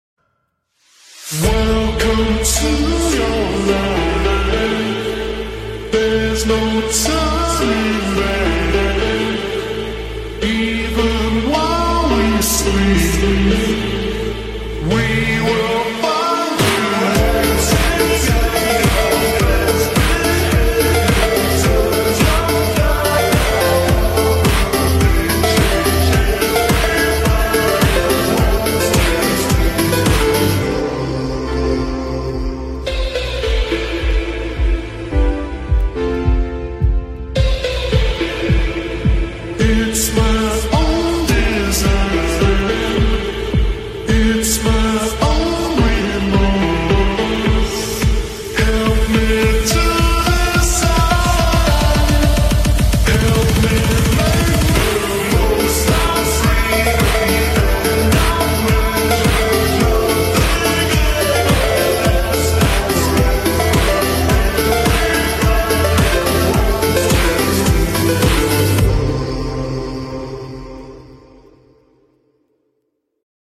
speaker man sound/ suara speaker sound effects free download
You Just Search Sound Effects And Download. tiktok comedy sound effects mp3 download Download Sound Effect Home